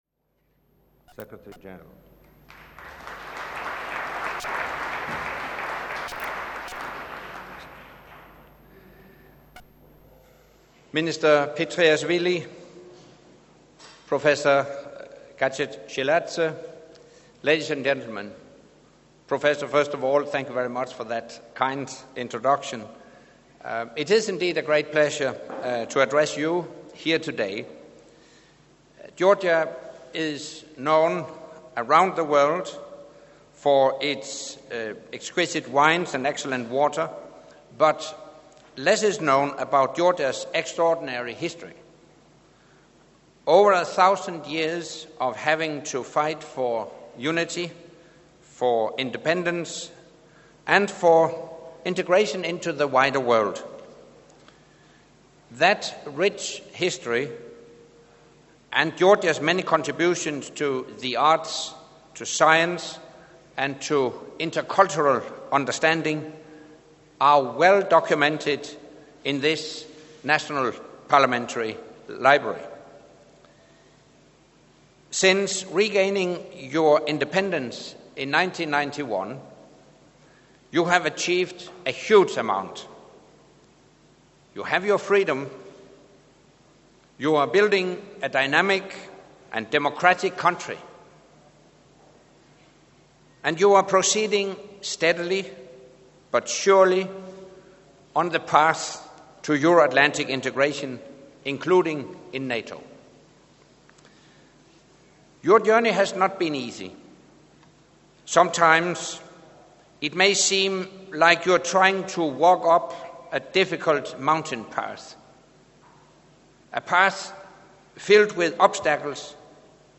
''NATO and Georgia – on the right path'' - Keynote speech by NATO Secretary General Anders Fogh Rasmussen at the National Library of Georgia, Tbilisi, Georgia
Speech to students by NATO Secretary General Anders Fogh Rasmussen at National Library, Tbilisi